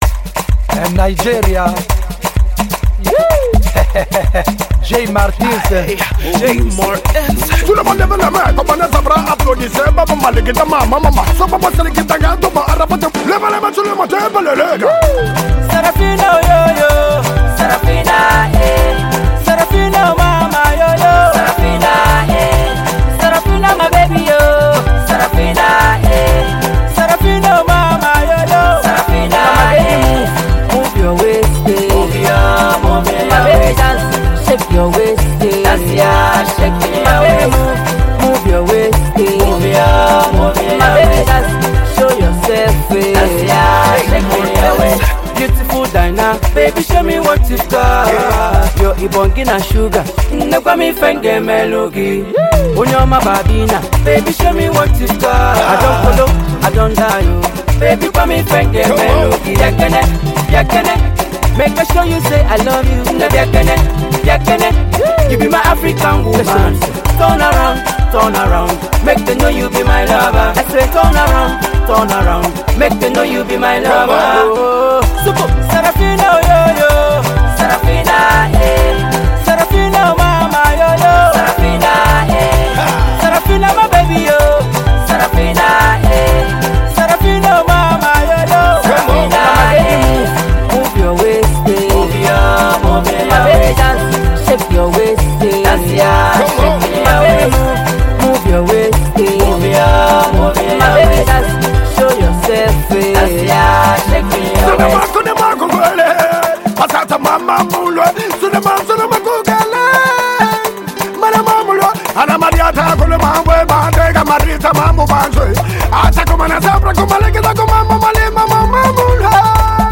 Home » Highlife